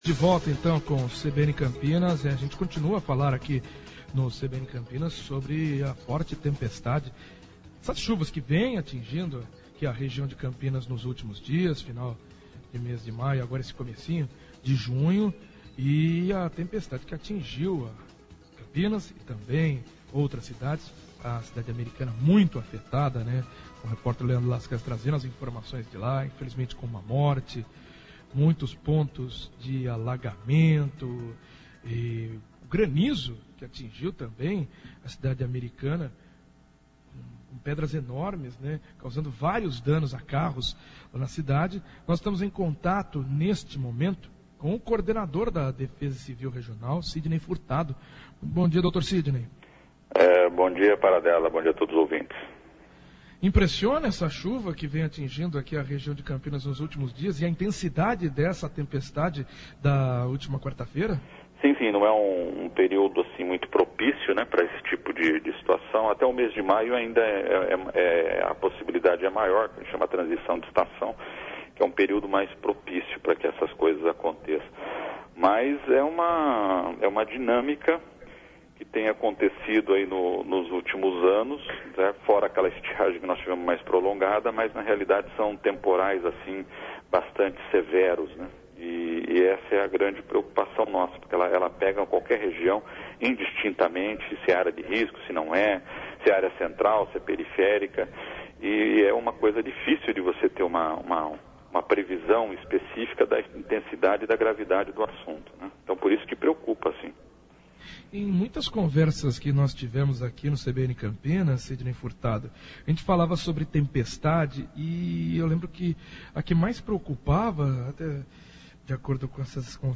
Diretor da Defesa Civil,Sidney Furtado fala sobre as chuvas de ontem e os estragos em nossa região